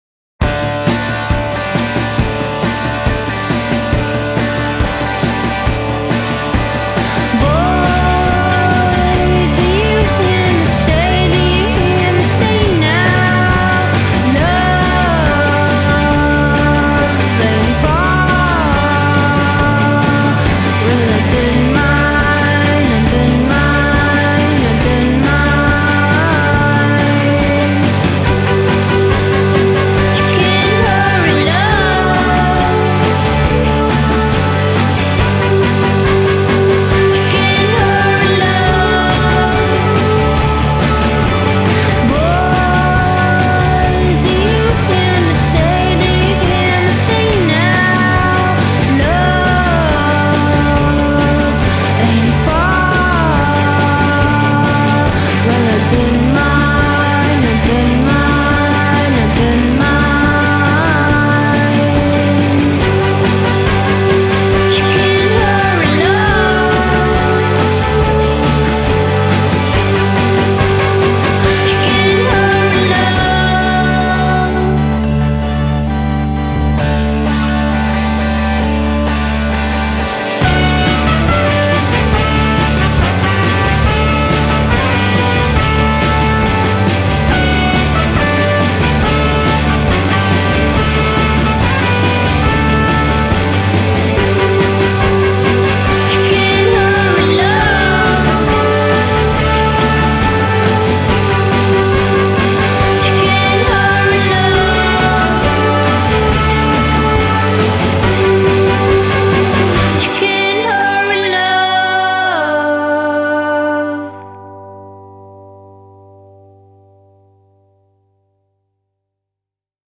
With the charge of Spector-on-speed energy of this song